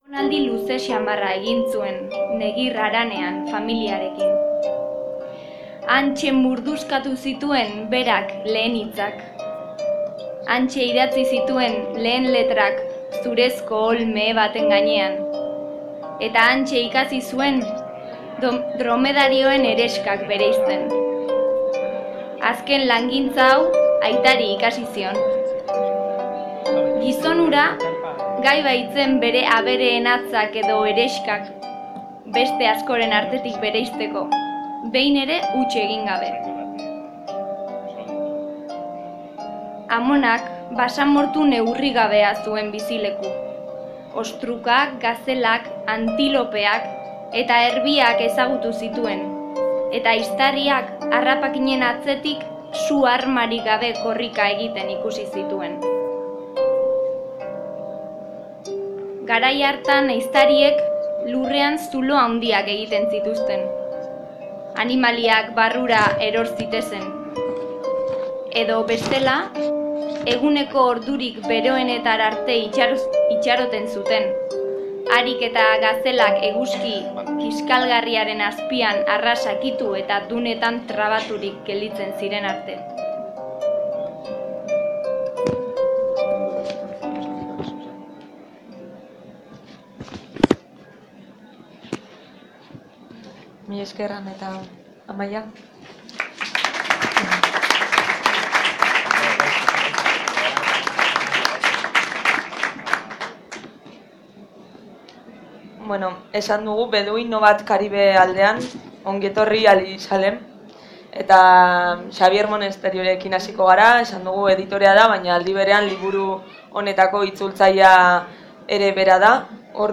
Beduino bat Karibe aldean liburuaren aurkezpena #50DA
Aurten Erroa argitaletxeak bi liburu eta disko bat aurkeztu ditu Durangoko Azokan.